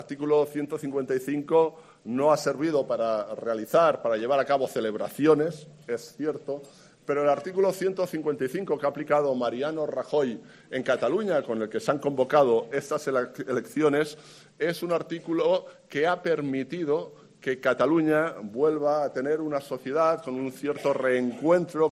Ante afiliados y simpatizantes del Penedès, el candidato popular ha reivindicado que la aplicación del artículo 155 "ha devuelto la estabilidad a Cataluña".